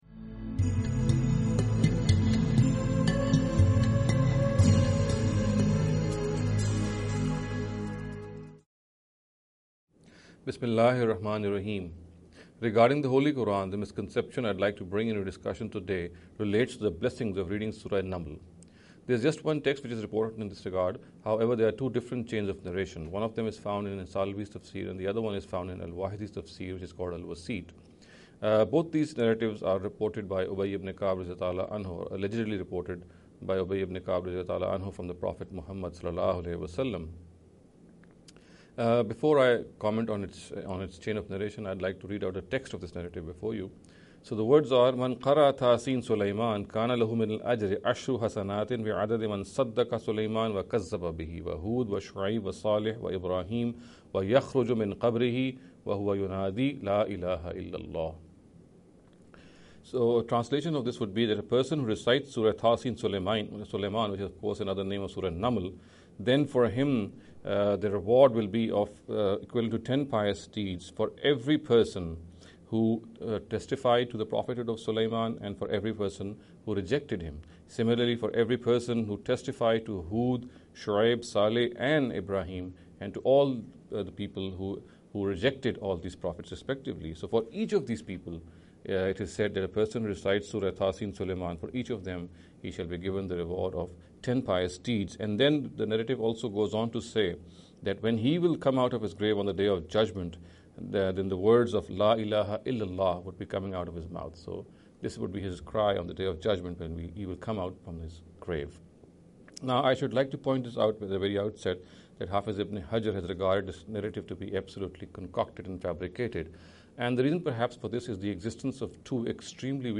This lecture series will deal with some misconception regarding the Holy Quran. In every lecture he will be dealing with a question in a short and very concise manner.